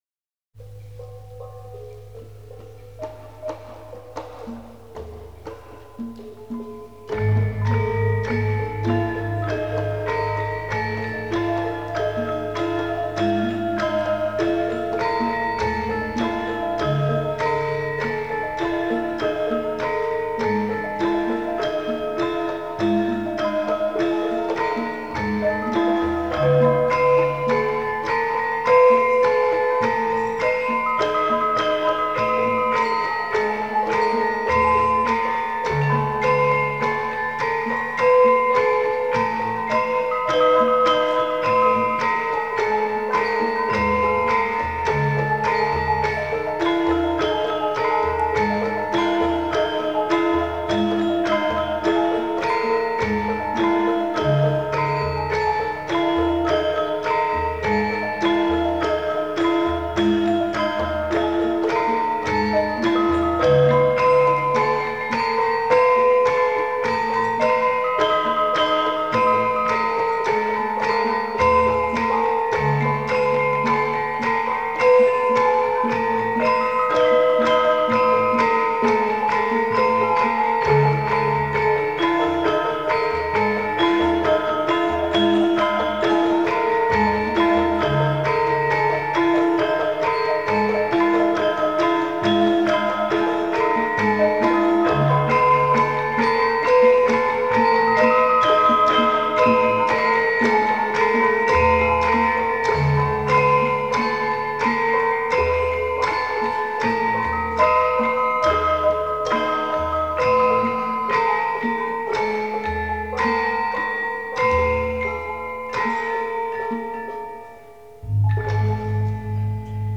通常在各地民俗音乐节/艺术节的现场进行录音
有不少都是mono音轨